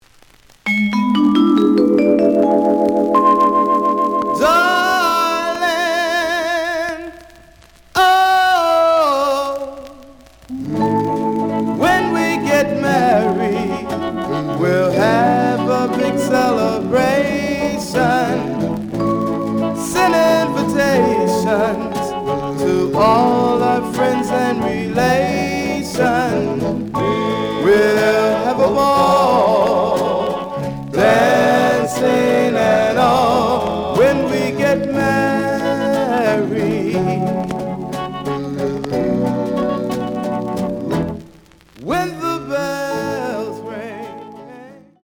The audio sample is recorded from the actual item.
●Genre: Rhythm And Blues / Rock 'n' Roll
Some click noise on A side due to scratches.